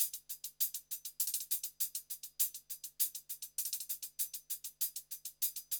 HIHAT LOP7.wav